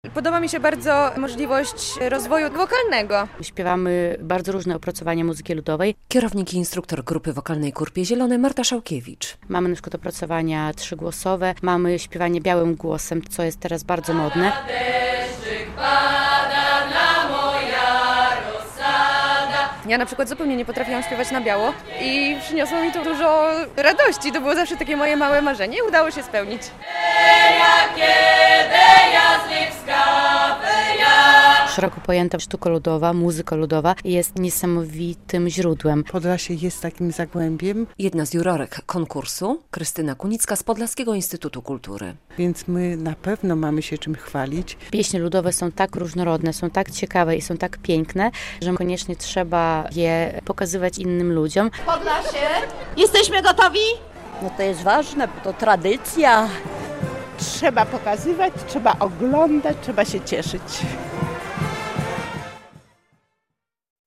Ogólnopolski finał festiwalu "Jawor - u źródeł kultury"- relacja